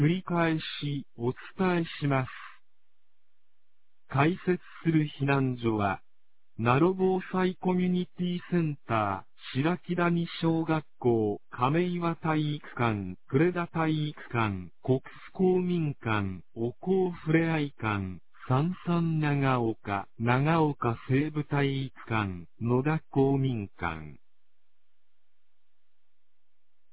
放送音声
2024年08月08日 21時38分に、南国市より放送がありました。